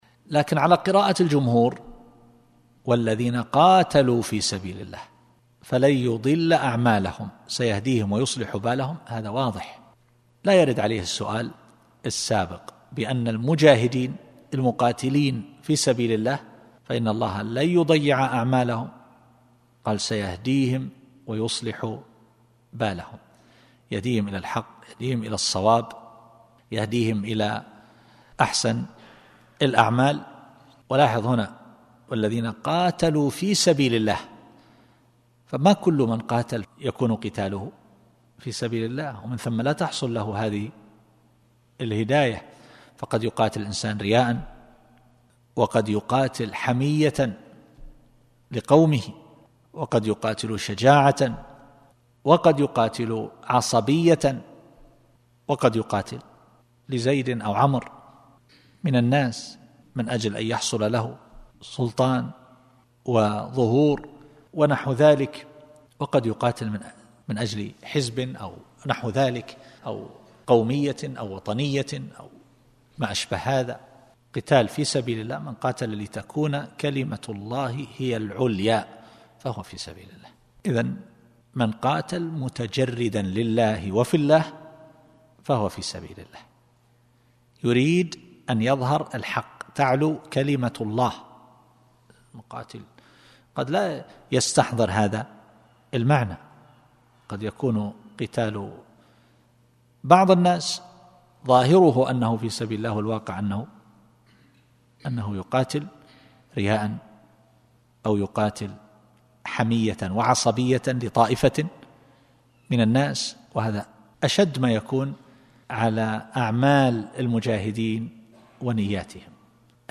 مقطع من درس المصباح المنير عند سورة محمد. التصنيف: قضايا إسلامية معاصرة